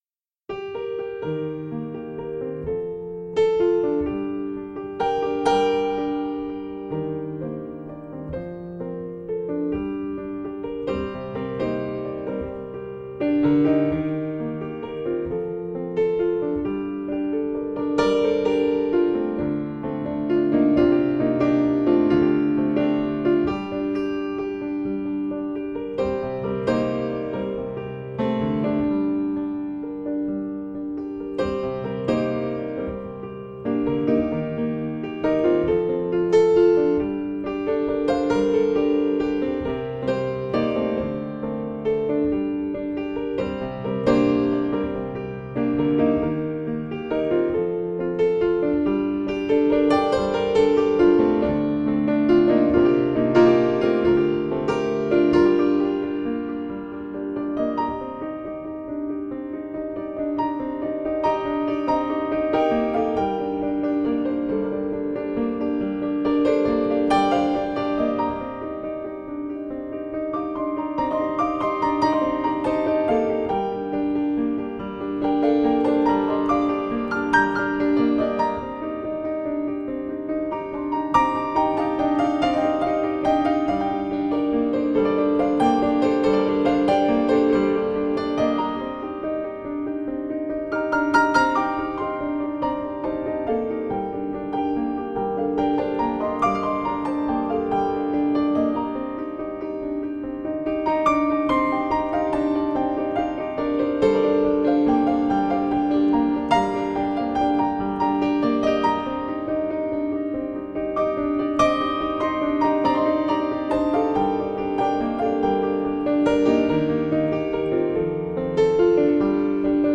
我们分明听到了很多奇妙的声音：露珠滴落， 叶片飞舞，风鸣松涛，鸟声嘀啁。